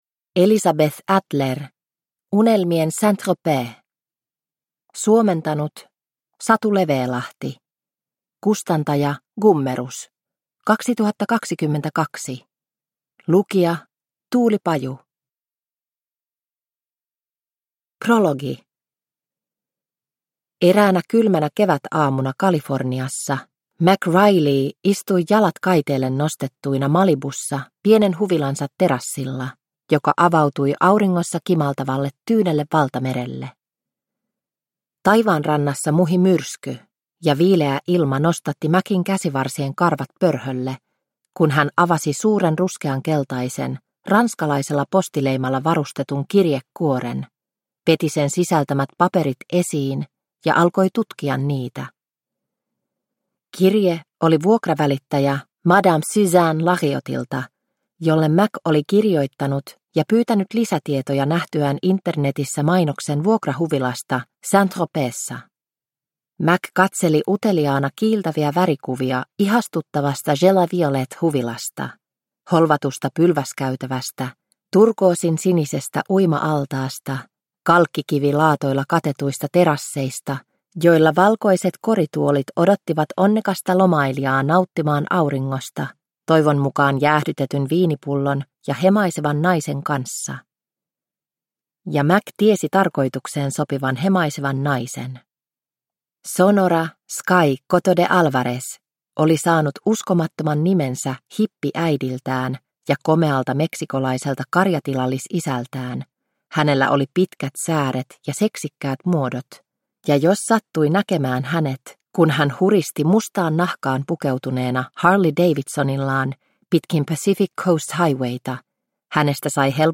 Unelmien Saint-Tropez – Ljudbok – Laddas ner